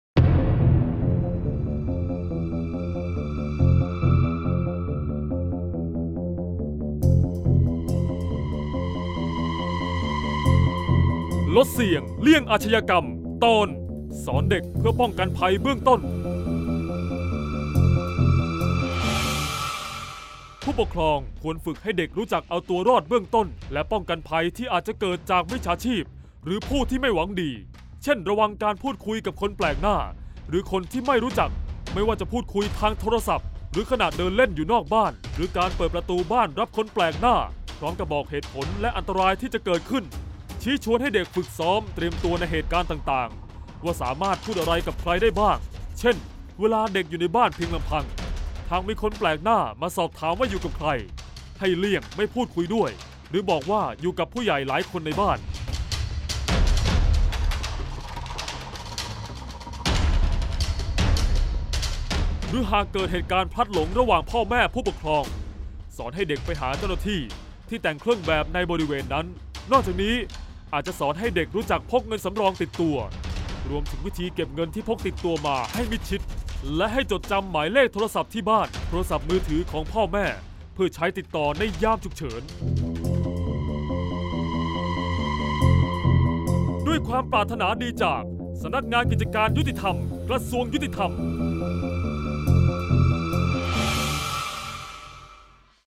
เสียงบรรยาย ลดเสี่ยงเลี่ยงอาชญากรรม 40-สอนเด็กป้องกันภัยเบื้องต้น